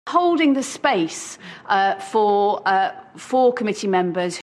The word for may be pronounced strongly if it isn’t connected to an immediately following word. This can occur when the speaker hesitates:
for_uh_four.mp3